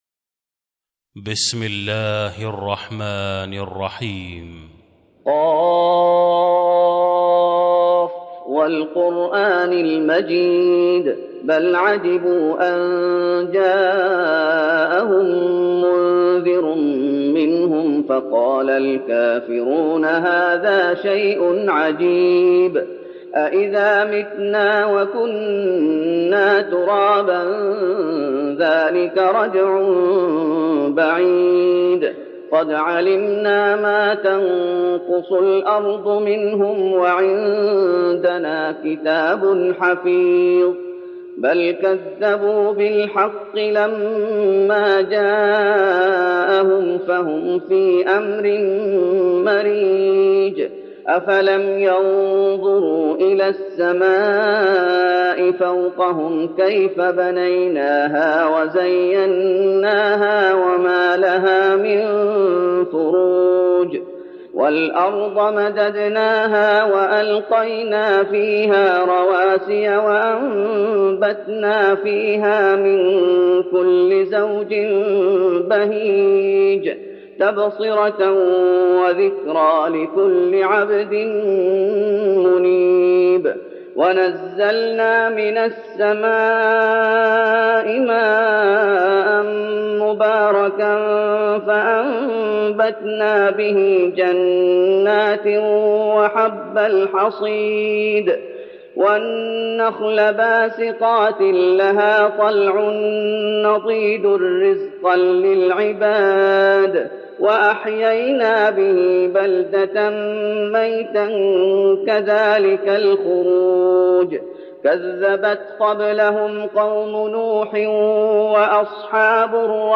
تراويح رمضان 1412هـ من سورة ق Taraweeh Ramadan 1412H from Surah Qaaf > تراويح الشيخ محمد أيوب بالنبوي 1412 🕌 > التراويح - تلاوات الحرمين